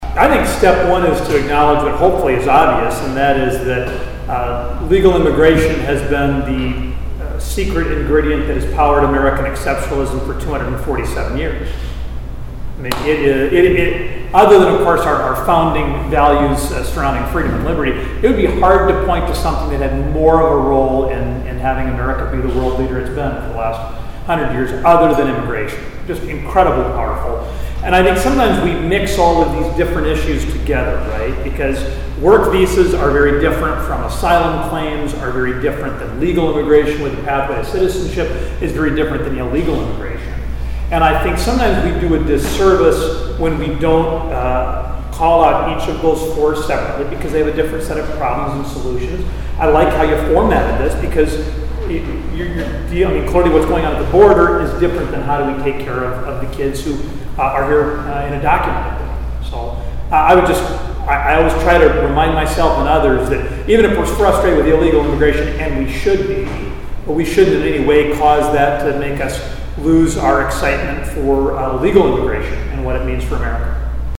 Americans for Prosperity hosted a lunch Tuesday in Huron at Top Floor Events.
US Representative Dusty Johnson was the featured speaker on the topic of immigration and the southern border security.  Johnson said when dealing with the issue it is important to compare the different forms of immigration.